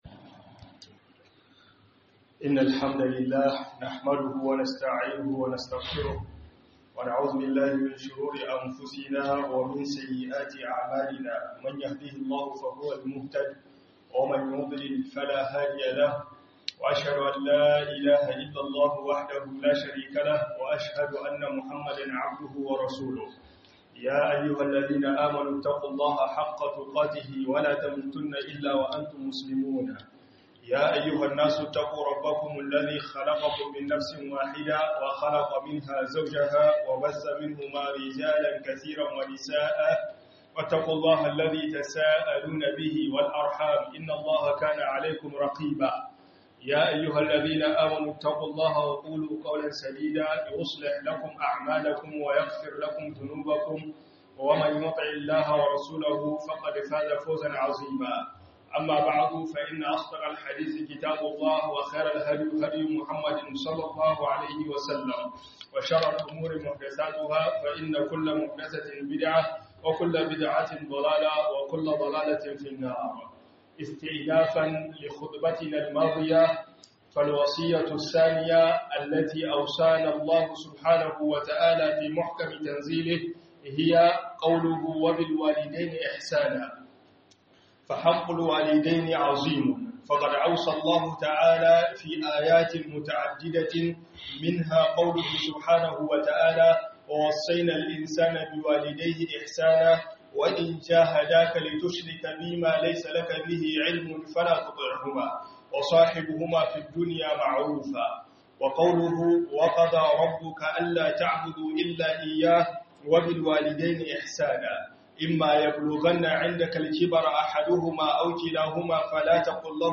WASIYOYIN ANNABi Guda 10 - HUDUBA